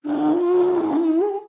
moan5.wav